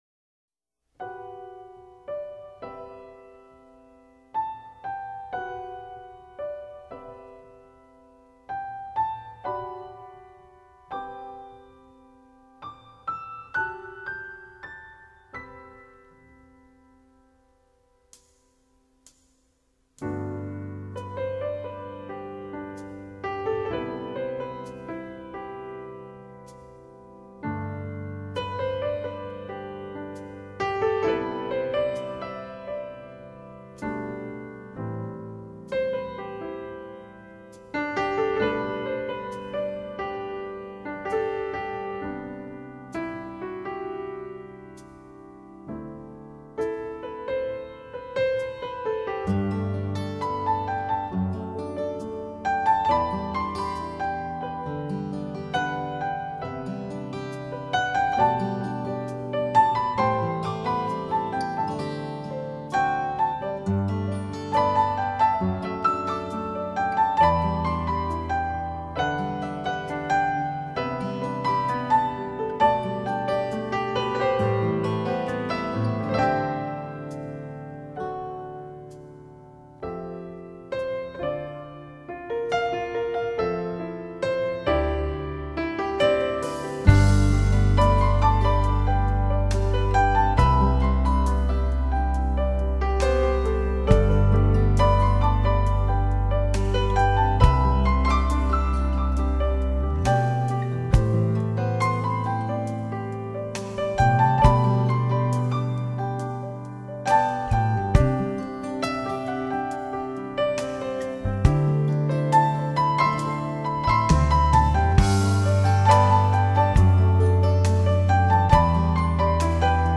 Piano
Producer New Age音乐家， 拥有卓越的感性和才能， 在韩国及海外皆受到众多乐迷的瞩目。